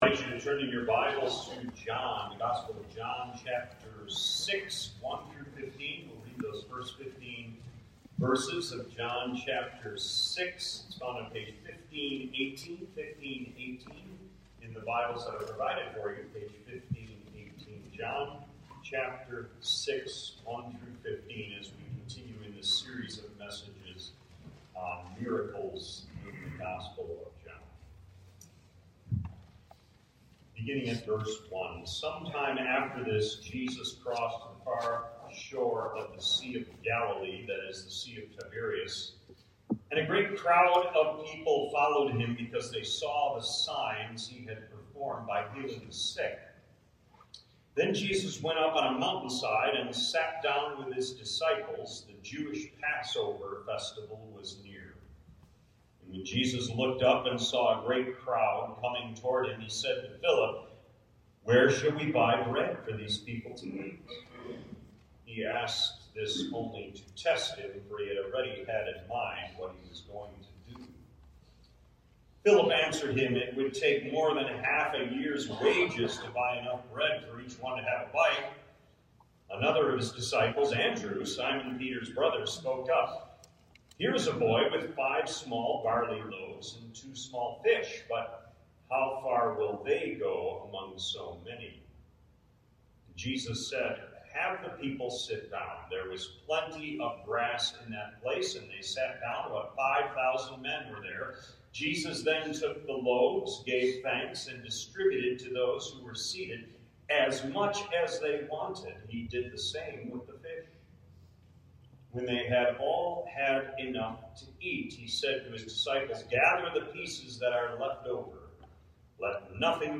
Note: Due to technican difficulties, the audio recording is very muffled.